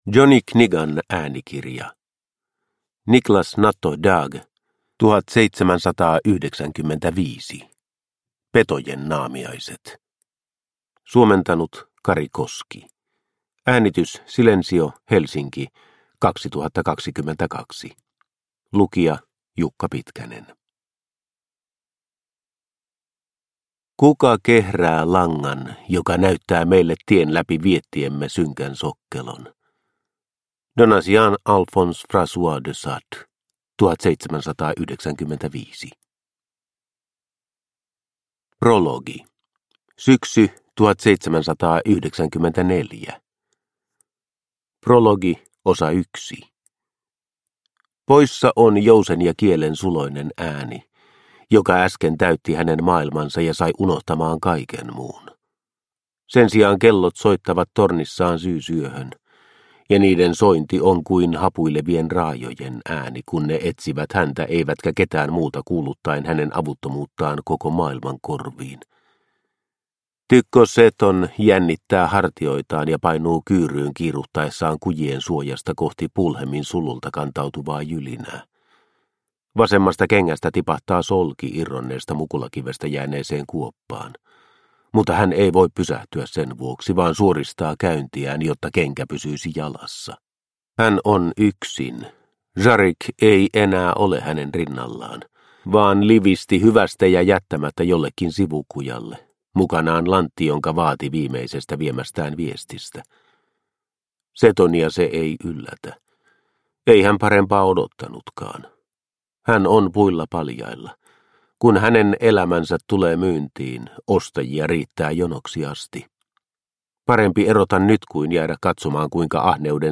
1795 – Ljudbok – Laddas ner